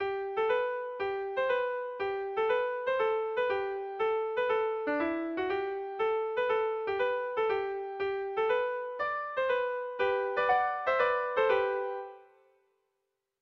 Tragikoa
Seikoa, berdinaren moldekoa, 6 puntuz (hg) / Sei puntukoa, berdinaren moldekoa (ip)
A1A2B1B2A3